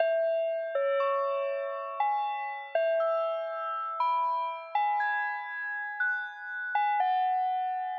孤独的圣诞之夜 120 Bpm
Tag: 120 bpm EDM Loops Bells Loops 1.35 MB wav Key : Unknown Logic Pro